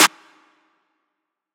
Perkys Calling Clap.wav